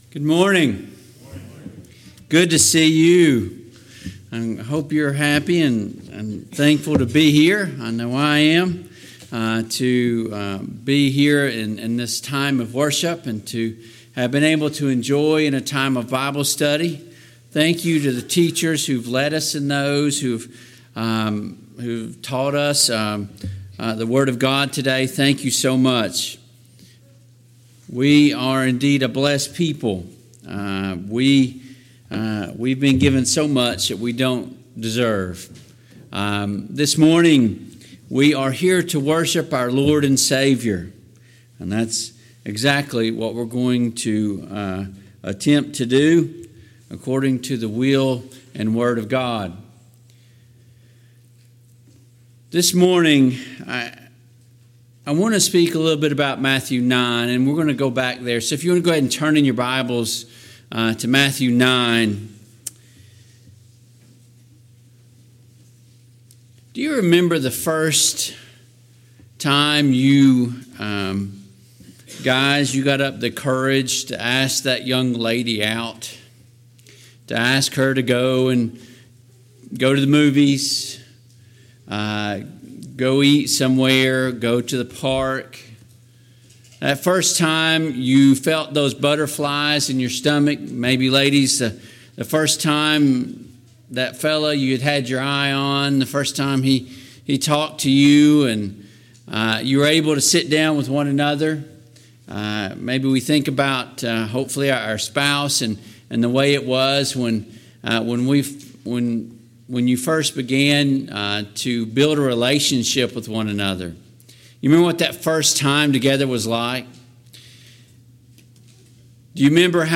Service Type: AM Worship Topics: Evangelism , Influence , Sin , Temptation